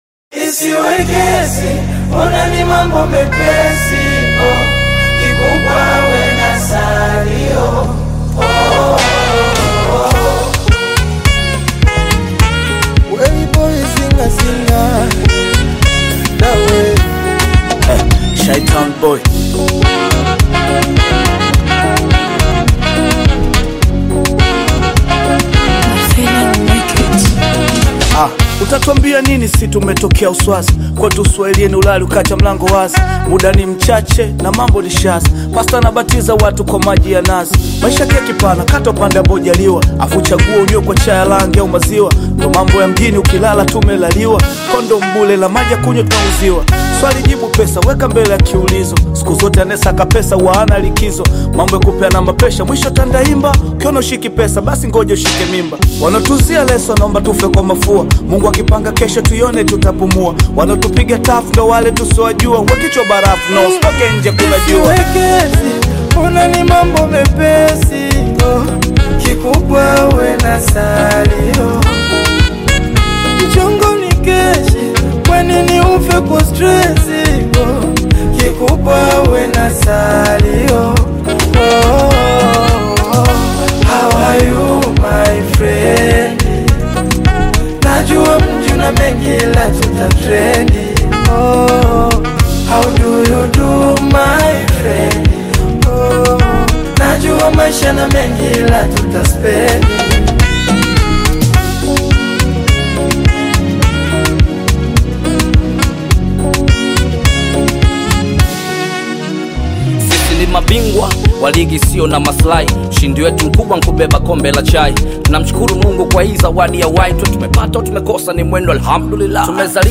Tanzanian Hip-Hop/Bongo Rap collaboration
lyrical rappers
energetic delivery